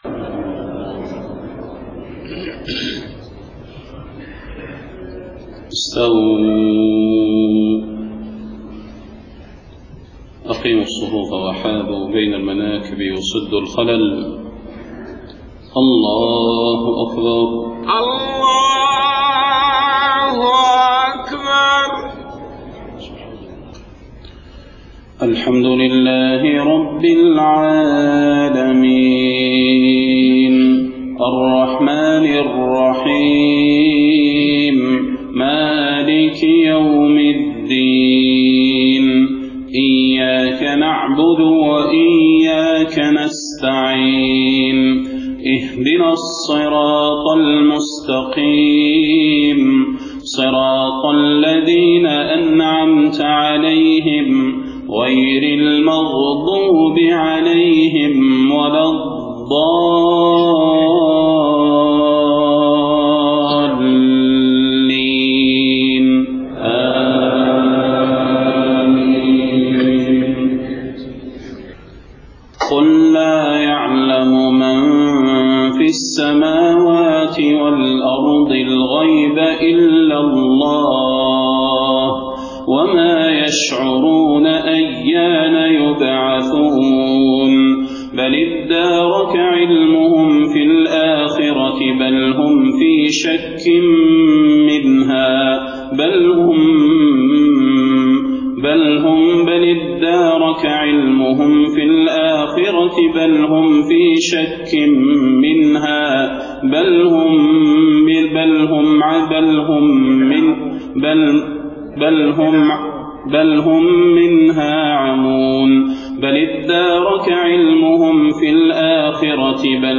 صلاة الفجر 3 ربيع الأول 1431هـ خواتيم سورة النمل 65-93 > 1431 🕌 > الفروض - تلاوات الحرمين